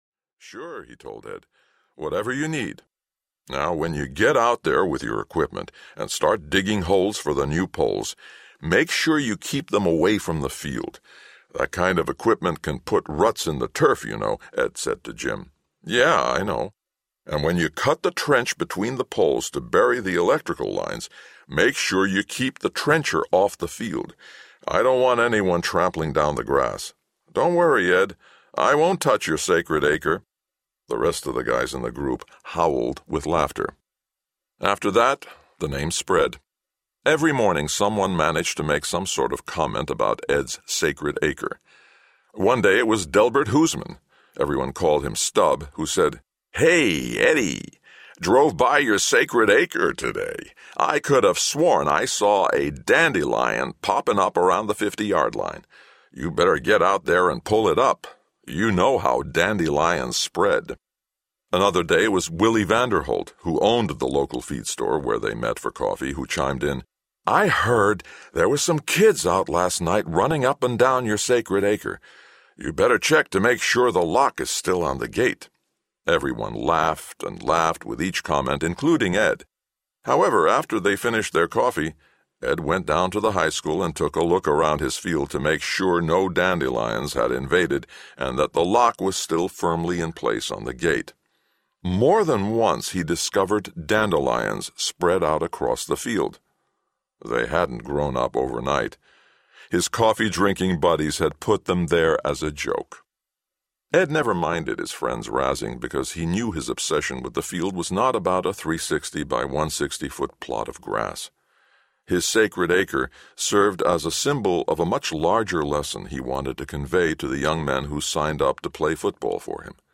The Sacred Acre Audiobook
Narrator
6.5 Hrs. – Unabridged